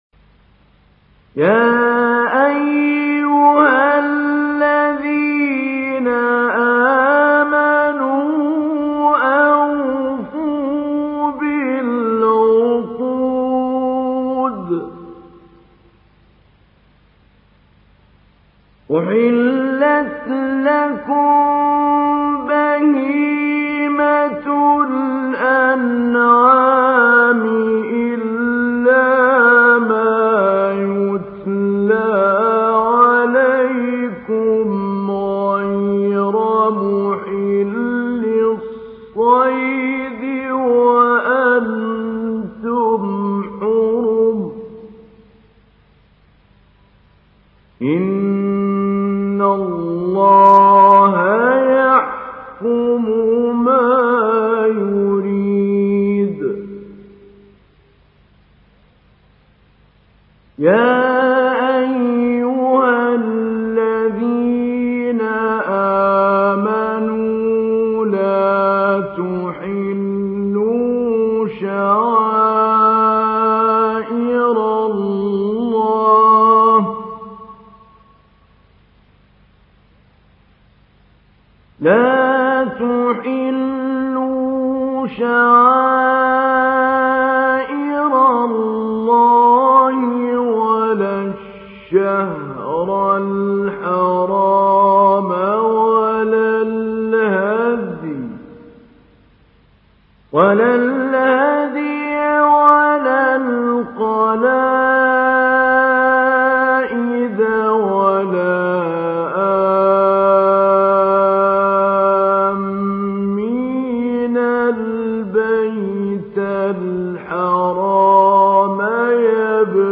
تحميل : 5. سورة المائدة / القارئ محمود علي البنا / القرآن الكريم / موقع يا حسين